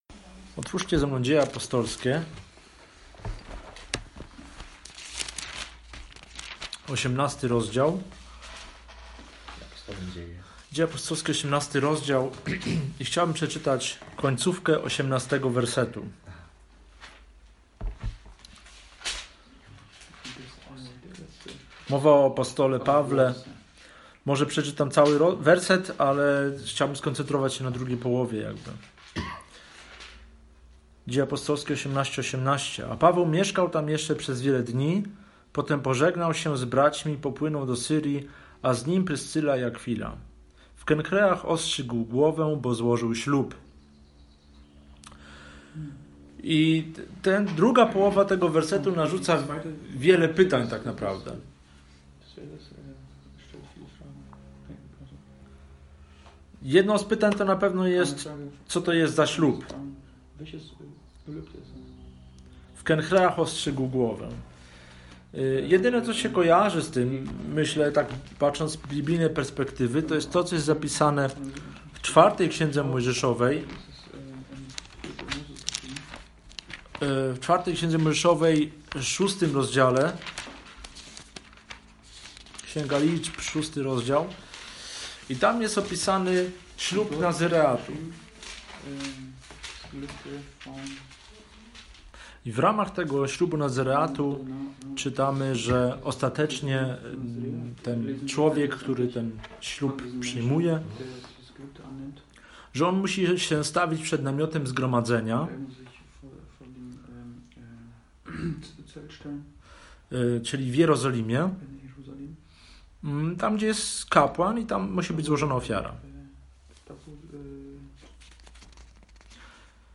Link do kazania